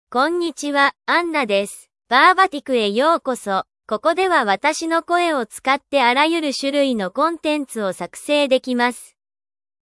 FemaleJapanese (Japan)
AnnaFemale Japanese AI voice
Anna is a female AI voice for Japanese (Japan).
Voice sample
Anna delivers clear pronunciation with authentic Japan Japanese intonation, making your content sound professionally produced.